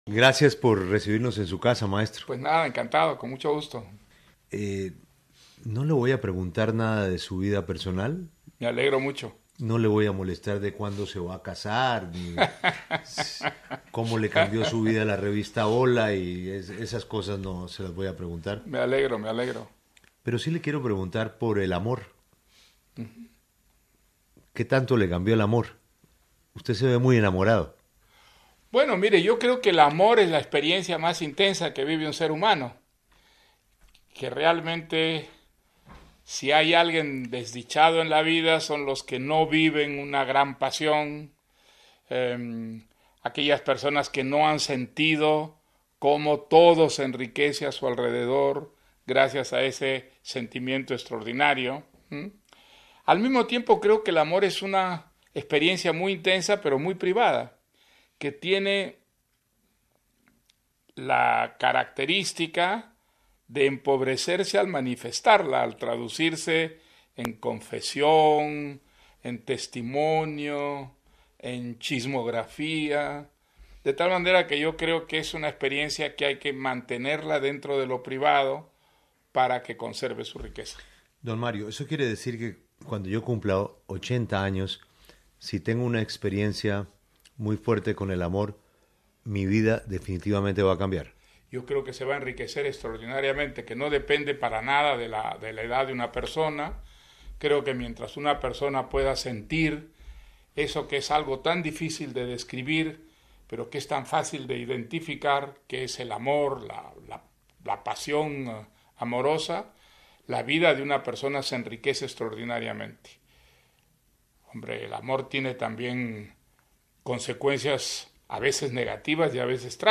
Literatura y política: reviva la entrevista de Julio Sánchez Cristo a Vargas Llosa en la casa del escritor